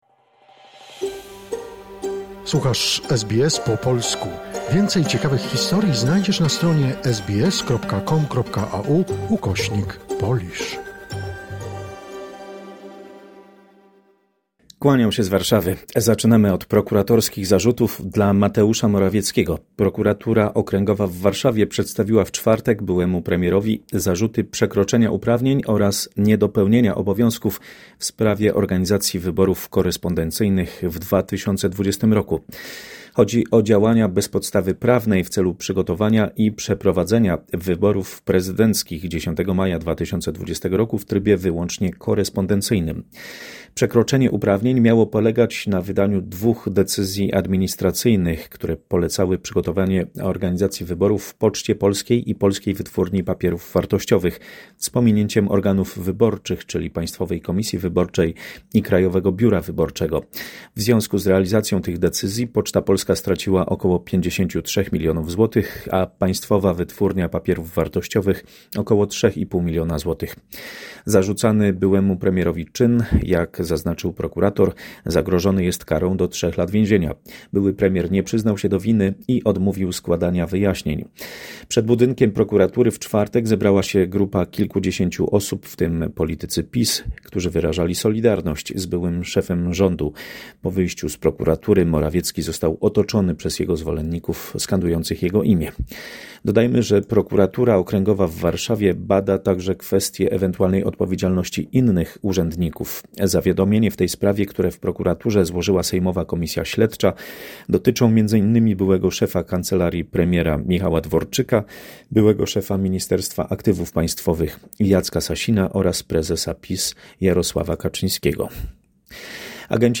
W dzisiejszej korespondencji